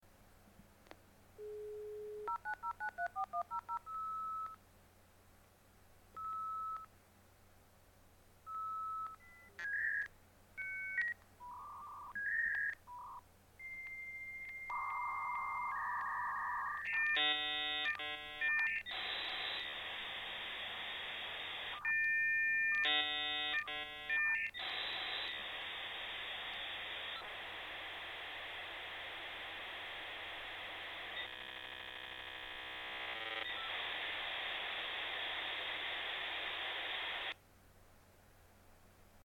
El momento más emocionante era cuando encendías el cacharrillo y comenzaba a emitir sonidos para la conexión. La estructura de sonido era típica, por lo que si un día no sonaba igual ya sabías que había problemas con la línea o algún otro lío para conectar. He aquí el sonido que marcó a una generación:
Dial_up_connection.ogg.mp3